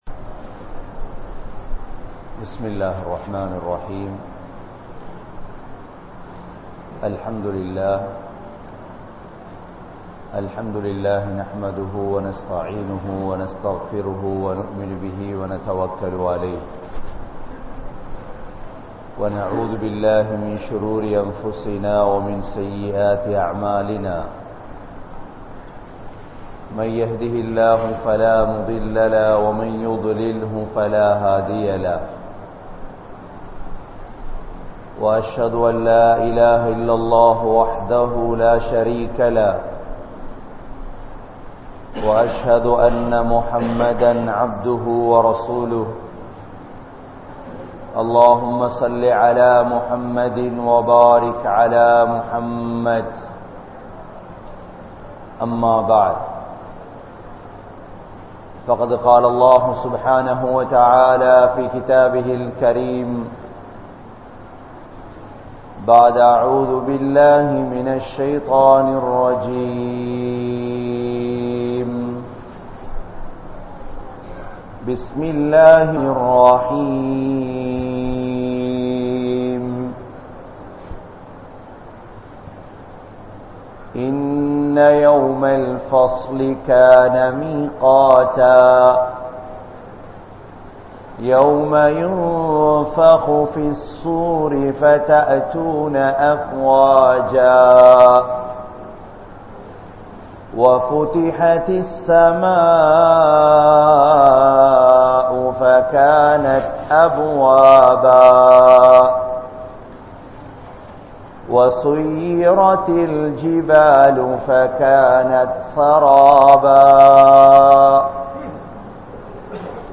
Ganniyam Veanduma? (கண்ணியம் வேண்டுமா?) | Audio Bayans | All Ceylon Muslim Youth Community | Addalaichenai
Grand Jumua Masjith